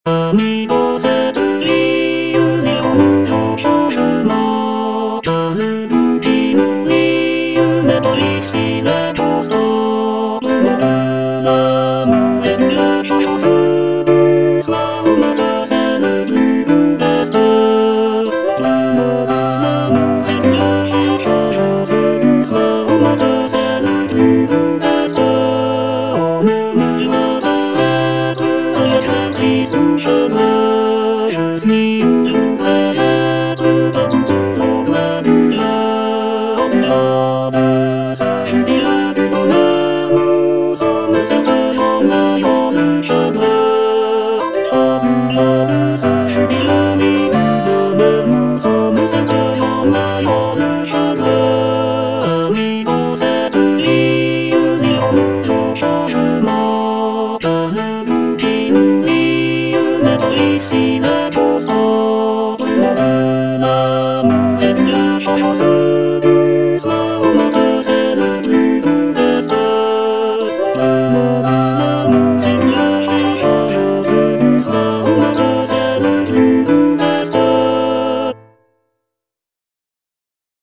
Tenor (Emphasised voice and other voices)
traditional French folk song